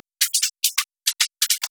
Percussion_01 B .wav